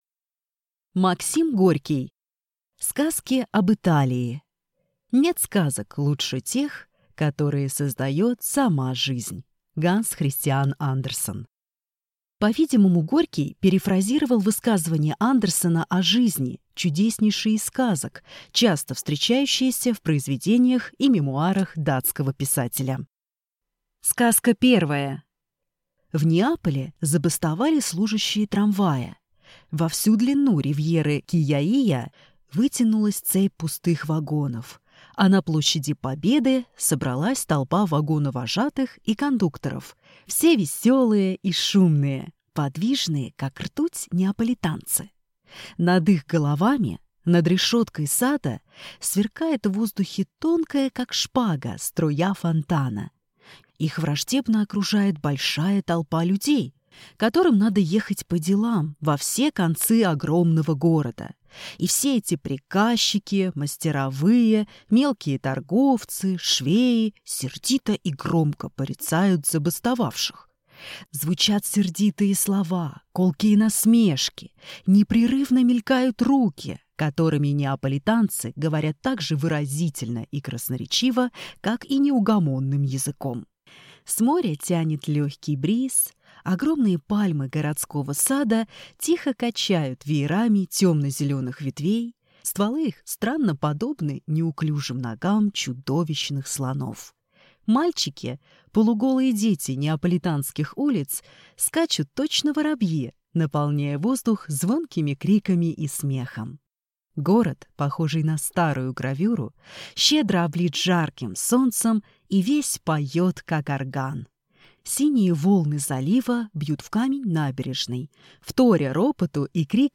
Аудиокнига Сказки об Италии | Библиотека аудиокниг